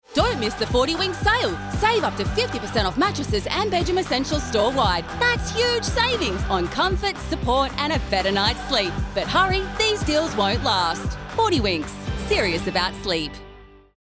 Female
English (Australian)
Adult (30-50)
Radio / TV Imaging
Hard Sell Commercial
Words that describe my voice are Humorous, Energetic, Professional.